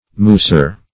Search Result for " musar" : The Collaborative International Dictionary of English v.0.48: Musar \Mu"sar\, n. An itinerant player on the musette, an instrument formerly common in Europe.
musar.mp3